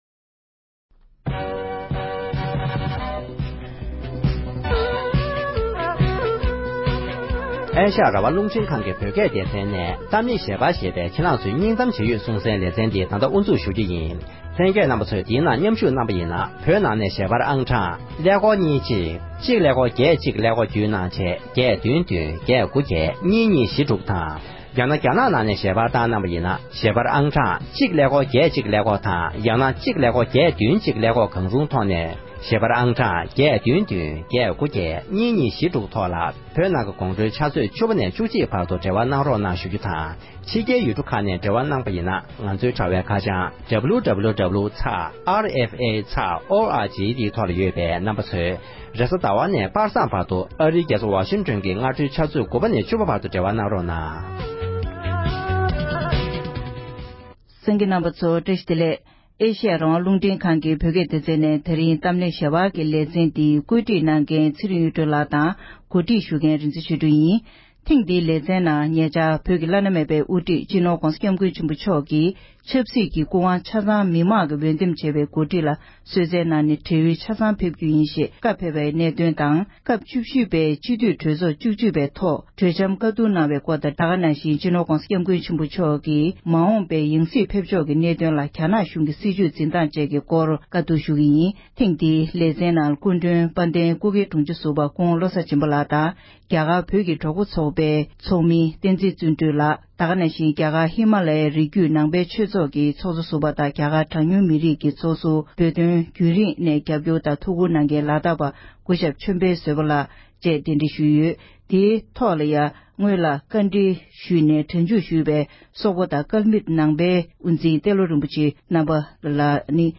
༸གོང་ས་མཆོག་གི་བགྲེས་ཡོལ་དུ་ཕེབས་རྒྱུའི་གསུང་འཕྲིན་དང༌མ་འོངས་ཡང་སྲིད་ཕེབས་ཕྱོགས་ཐད་བཀའ་མོལ།